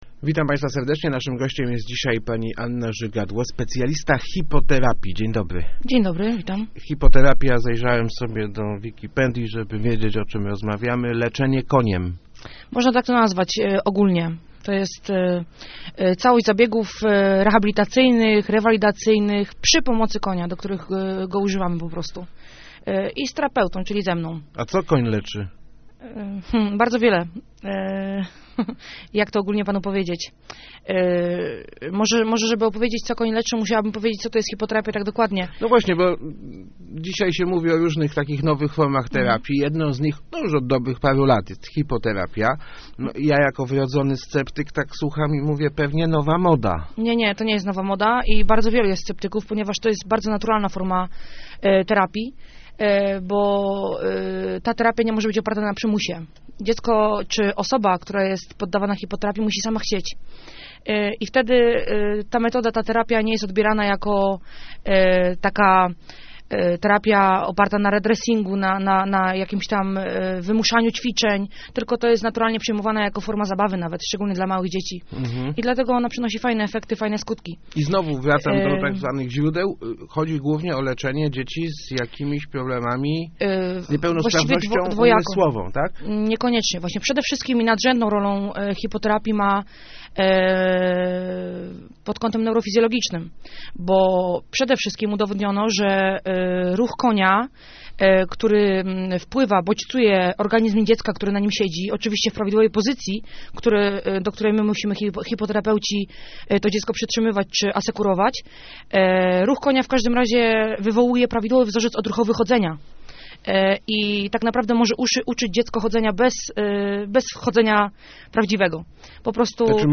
Leszno, Portal Regionu, regionalne, lokalne, radio, elka, Kościan, Gostyń, Góra, Rawicz, Wschowa, żużel, speedway, leszczyński, radio, gazeta, dodatek